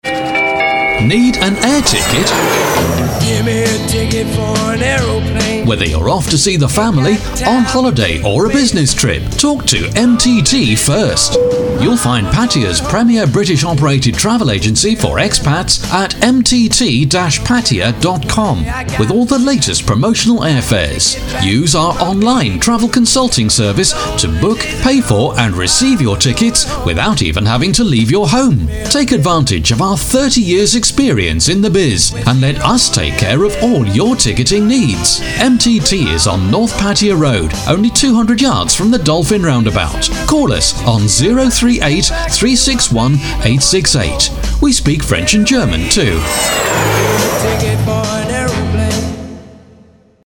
Sprecher englisch (uk).
Sprechprobe: Sonstiges (Muttersprache):
Voice over talent English (British)(native Speaker).